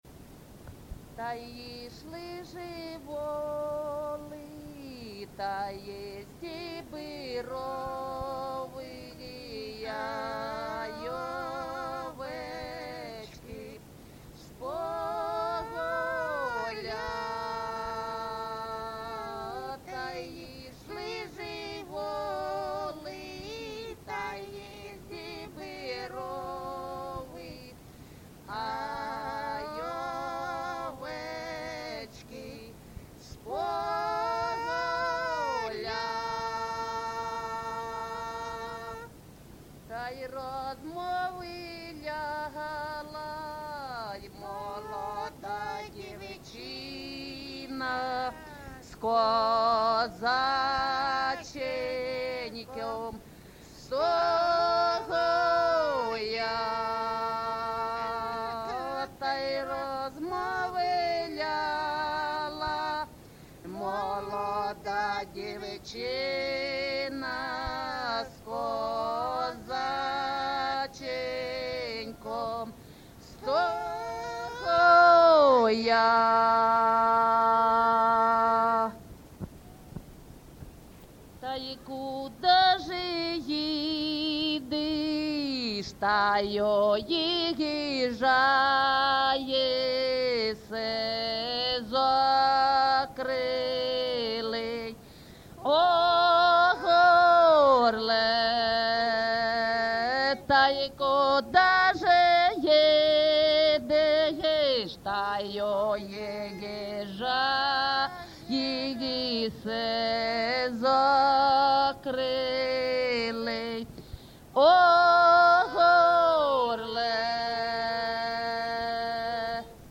ЖанрПісні з особистого та родинного життя
Місце записус-ще Калинівка, Бахмутський район, Донецька обл., Україна, Слобожанщина